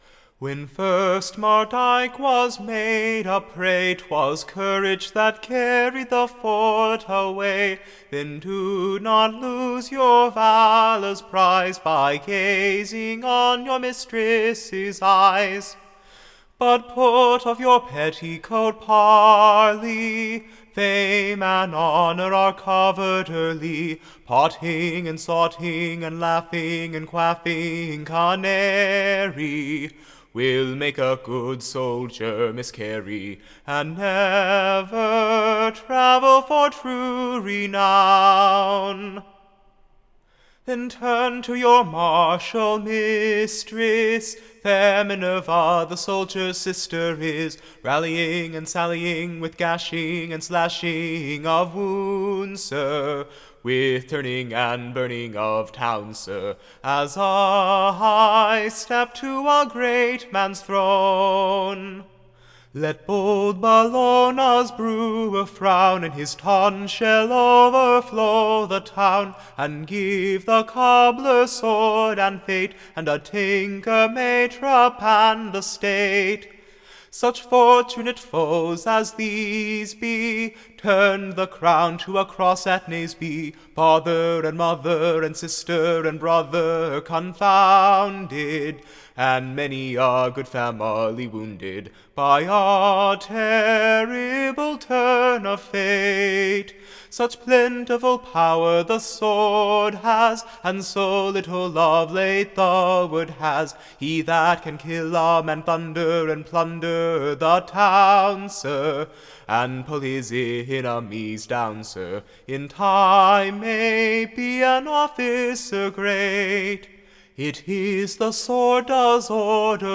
Recording Information Ballad Title MARDIKE: / OR, / The Soldiers Sonnet of his Sword / Sung to the ORGAN. Tune Imprint Standard Tune Title Mardyke Media Listen 00 : 00 | 11 : 15 Download e1.338.mp3 (Right click, Save As)